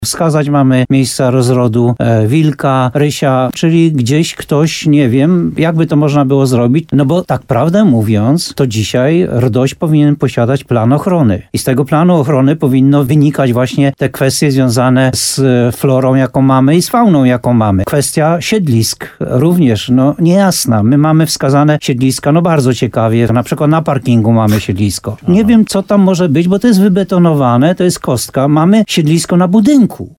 Oddala się perspektywa uruchomienia nowych wyciągów narciarskich w Szczawniku w grudniu tego roku. – Ten termin jest mocno zagrożony – powiedział radiu RDN Nowy Sącz burmistrz Muszyny Jan Golba.